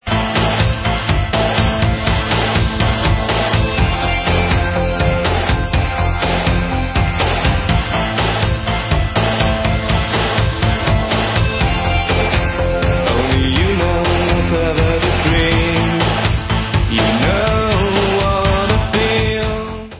Synthie-pop